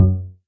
bassattack.ogg